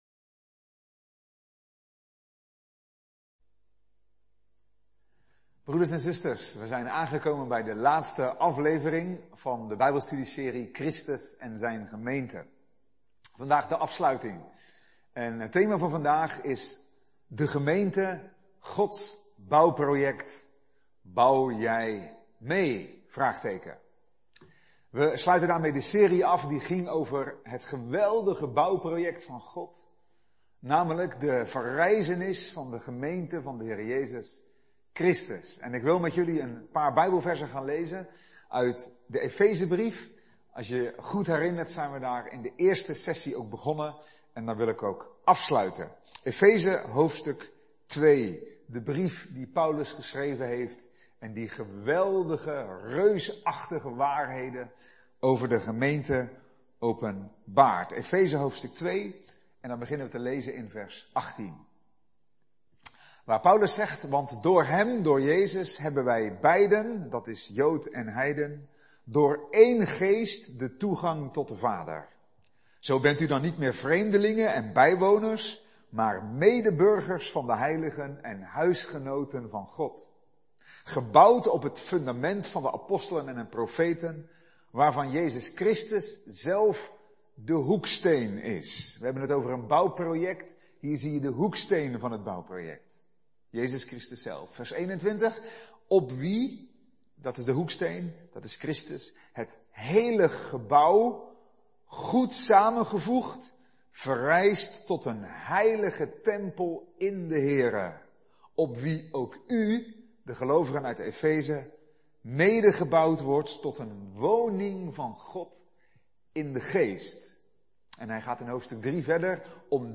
Een preek over 'De gemeente #11: Gods bouwproject! Bouw jij mee?'.